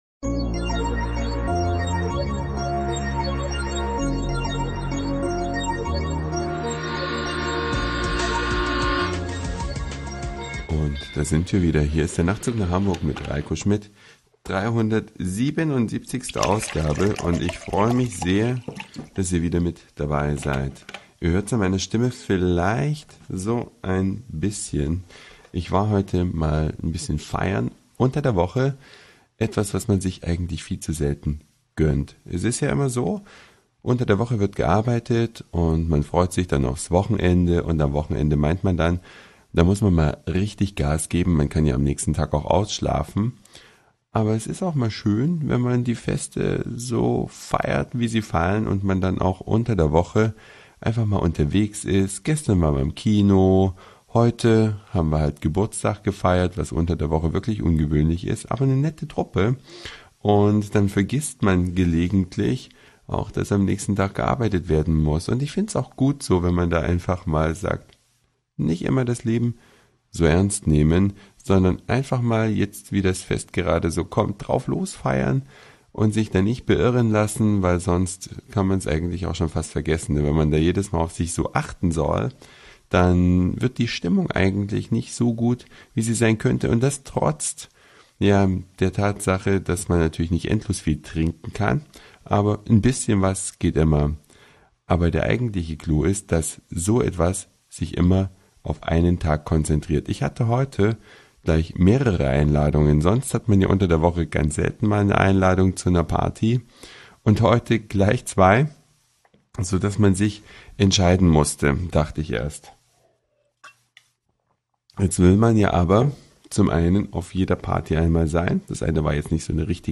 Ihr hört es bestimmt an der Stimme.